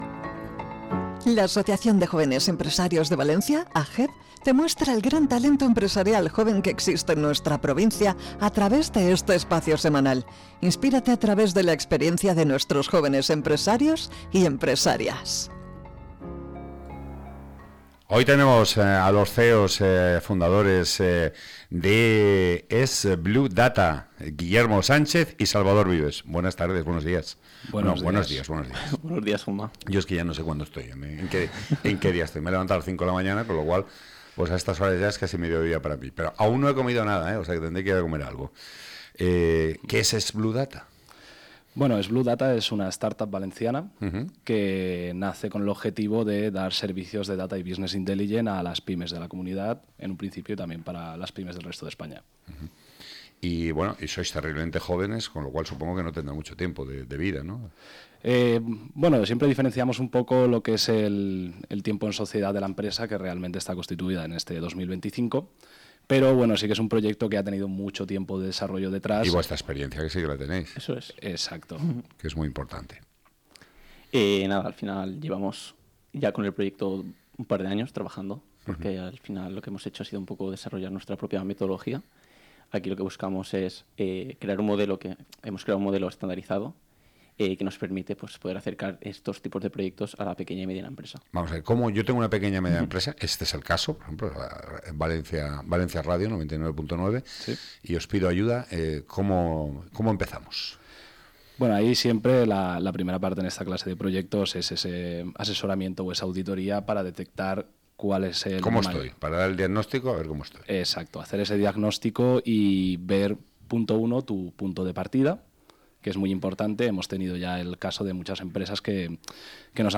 Entrevista
Durante su entrevista en el espacio de AJEV en 99.9 Valencia Radio, explicaron cómo han diseñado una metodología propia que permite a pequeñas empresas acceder a soluciones que antes solo estaban al alcance de grandes corporaciones.